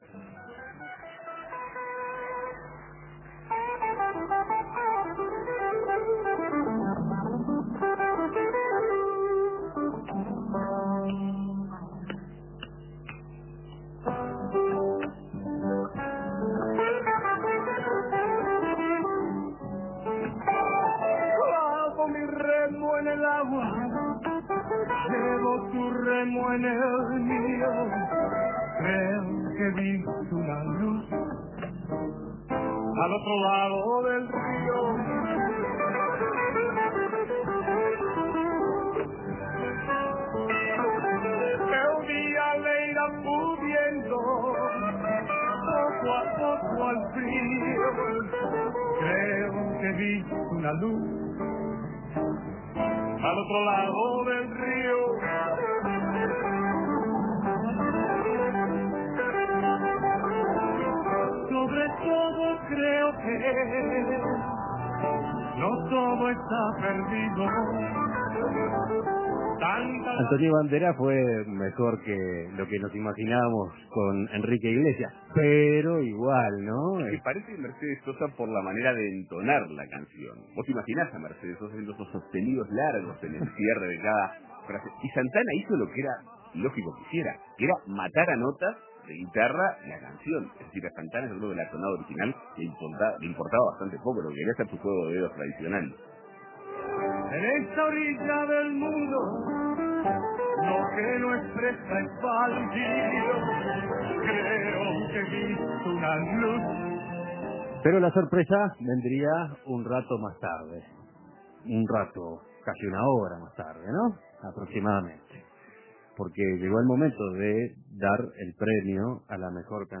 Escuche la canción cantada por Banderas, comentarios de la ceremonia y primeras declaraciones del músico uruguayo